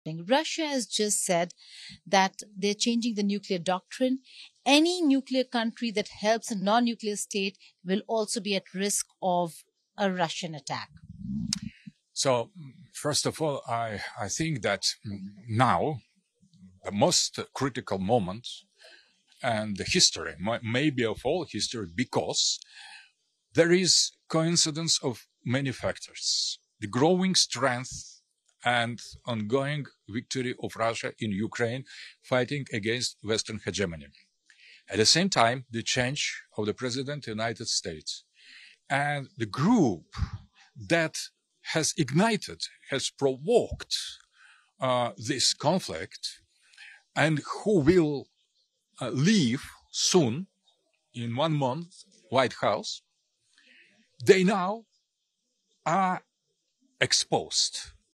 In this exclusive statement, Aleksandr Dugin warns that Russia will take military action against any nation providing nuclear weapons to Ukraine. Dugin, a prominent Russian philosopher and political theorist, emphasizes that such support for Ukraine could escalate tensions and provoke Russia into striking those countries.